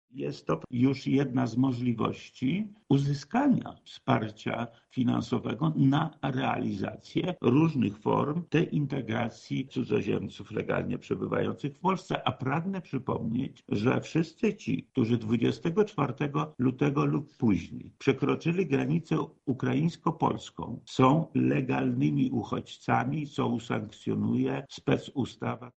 -mówi Lech Sprawka, wojewoda lubelski